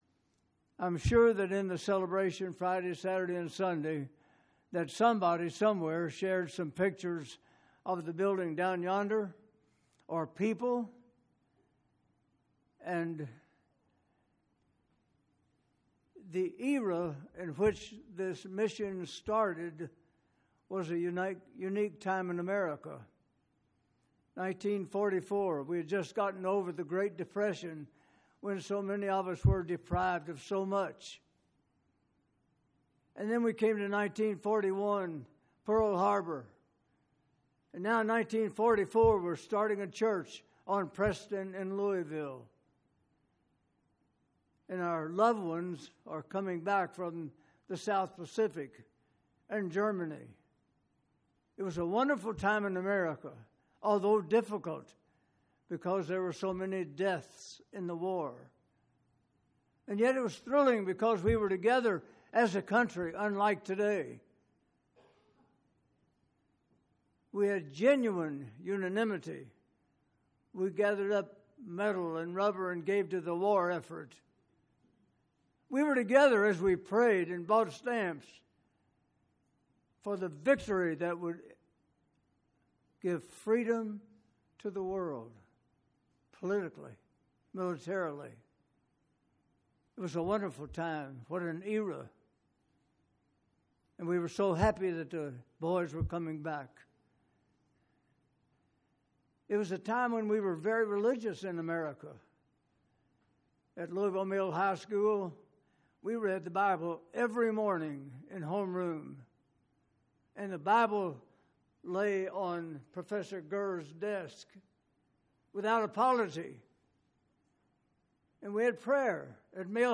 75th Anniversary Message